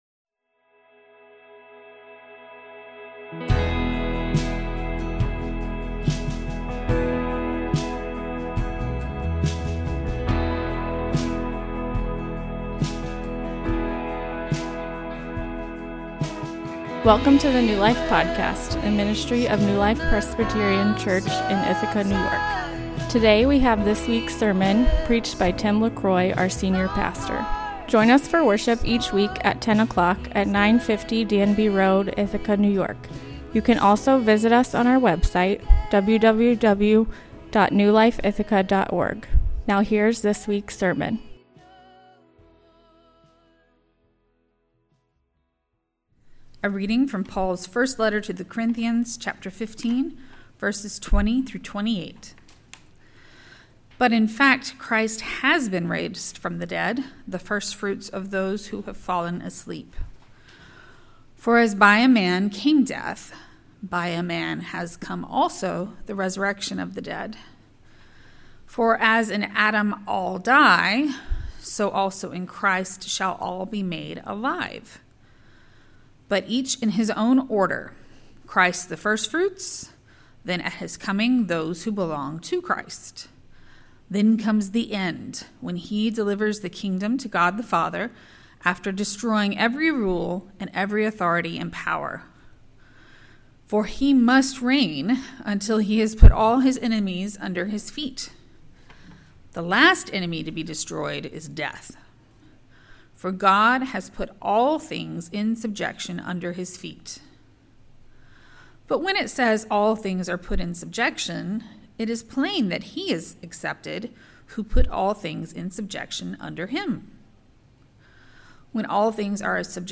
Sermon quotes: Well, it seems a good book – strange that the white people are no better, after having had it so long.